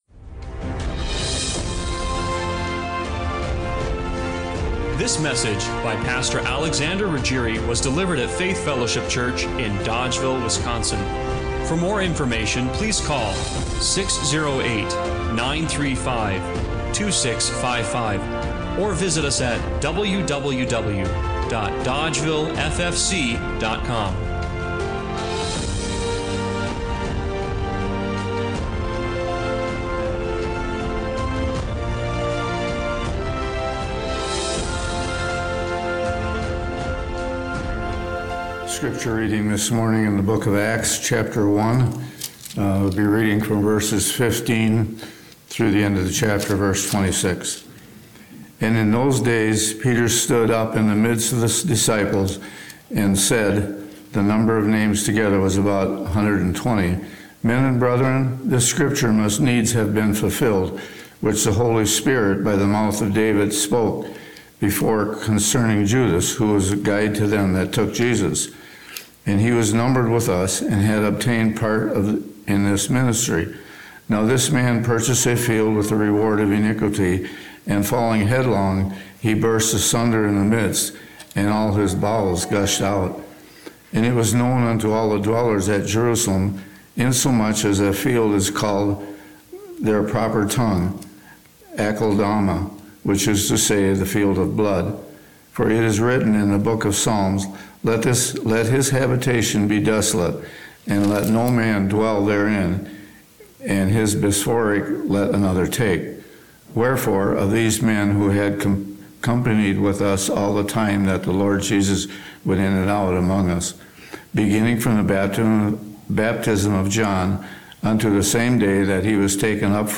Acts 1:15-26 Service Type: Sunday Morning Worship What if the seat God prepared for you is still empty… but not for long?